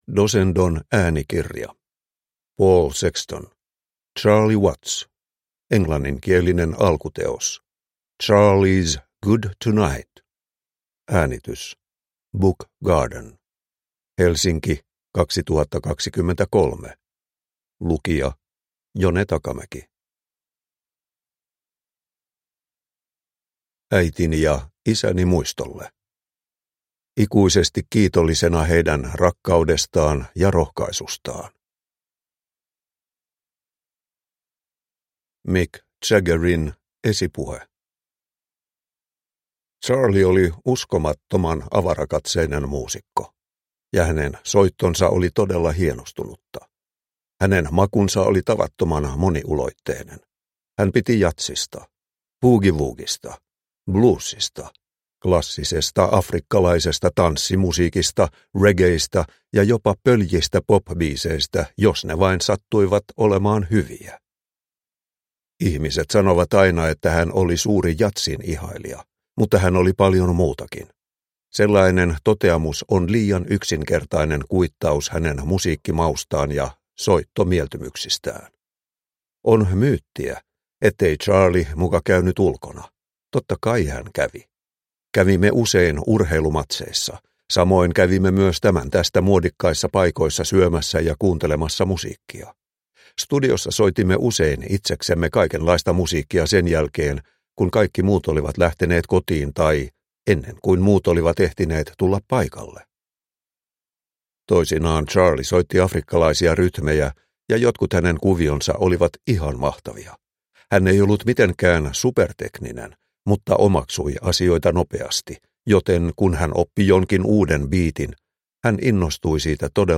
Charlie Watts – Ljudbok – Laddas ner